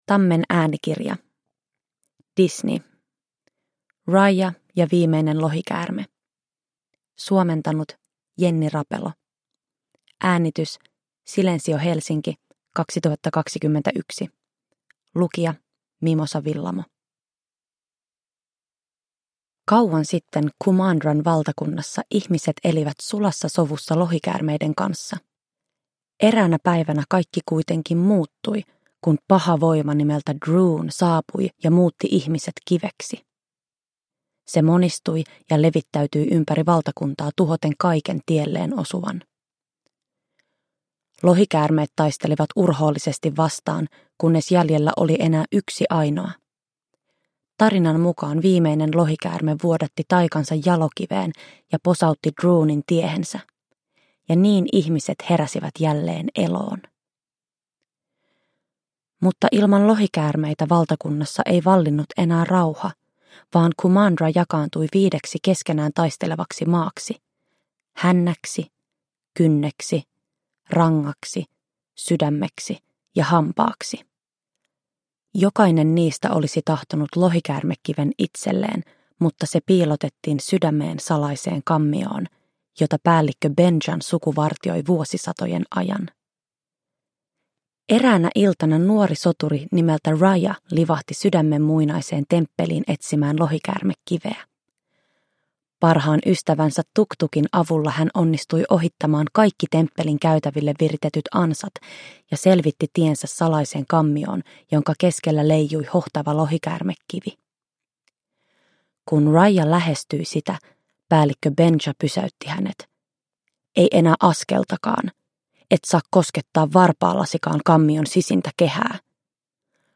Äänikirja kertoo yksityiskohtaisesti uuden Disney-animaatioelokuvan seikkailun.
Uppläsare: Mimosa Willamo